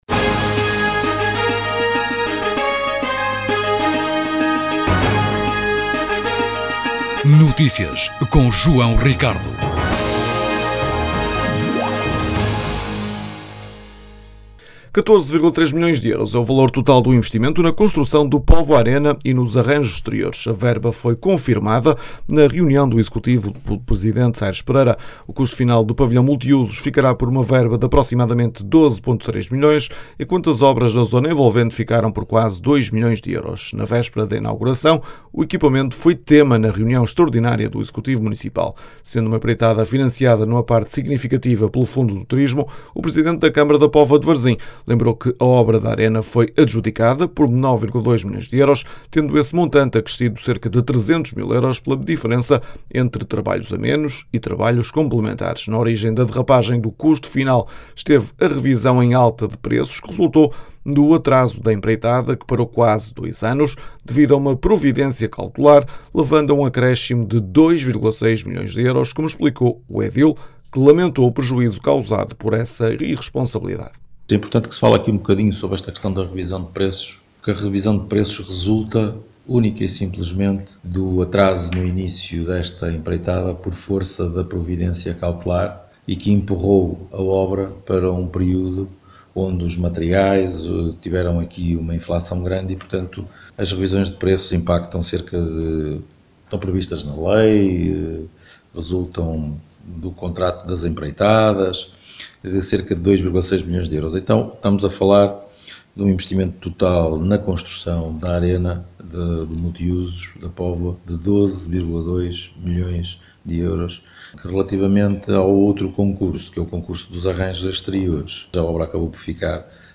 O custo final do pavilhão multiusos ficará por uma verba de aproximadamente 12.3 milhões de euros, enquanto as obras na zona envolvente chegaram a quase dois milhões de euros. Na véspera da inauguração, o equipamento foi tema na reunião extraordinária do executivo municipal.
As declarações podem ser ouvidas na edição local.